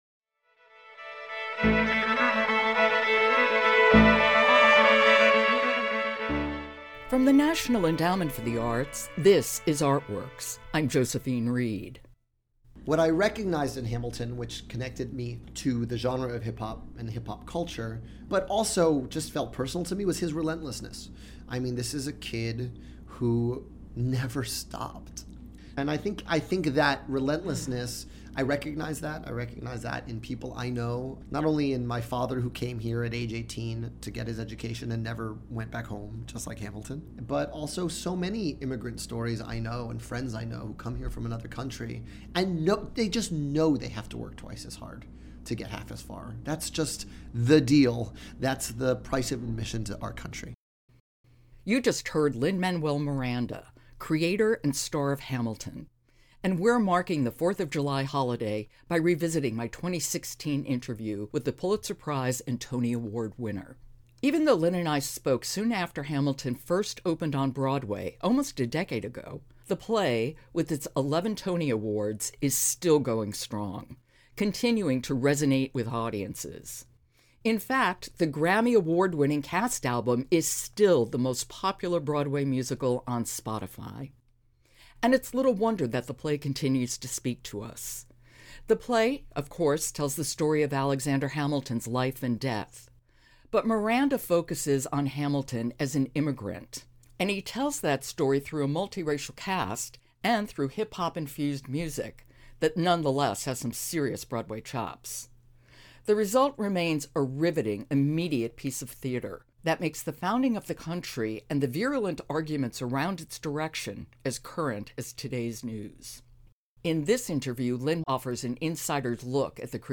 In this 2016 interview with Lin-Manuel Miranda, we talk about "Hamilton" and discuss the meticulous process behind the lyrics, the dynamic collaboration with the cast, and how hip-hop and diverse casting redefined Broadway and helped to reframe America's founding.